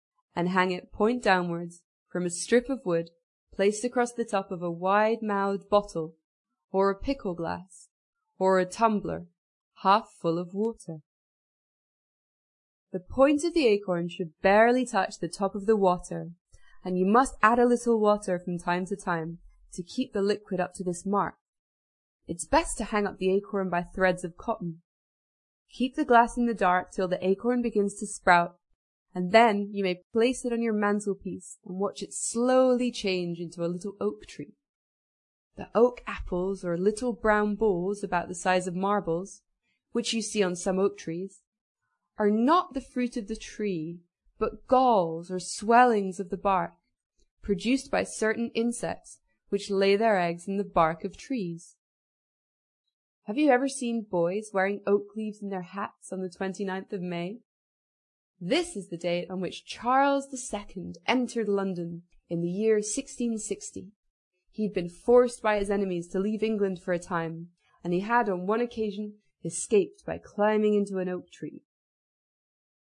在线英语听力室英国学生科学读本 第90期:结实的橡树(3)的听力文件下载,《英国学生科学读本》讲述大自然中的动物、植物等广博的科学知识，犹如一部万物简史。在线英语听力室提供配套英文朗读与双语字幕，帮助读者全面提升英语阅读水平。